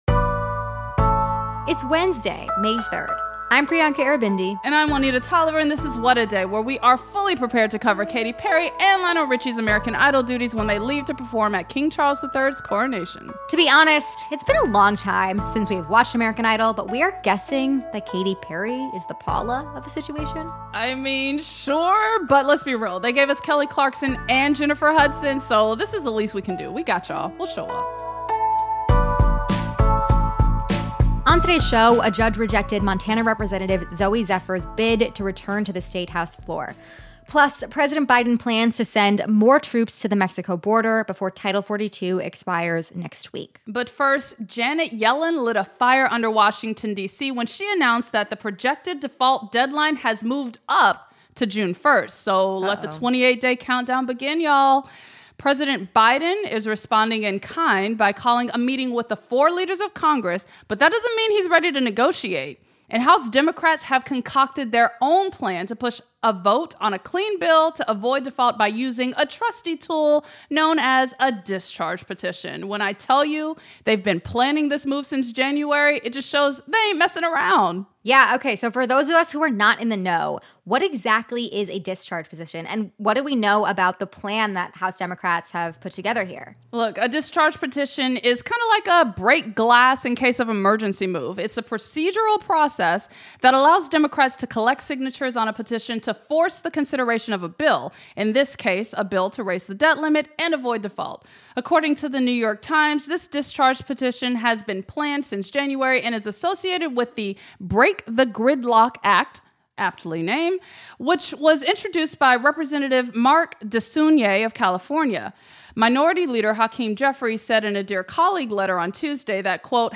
” joins us from the picket lines to talk about the issues driving the strike.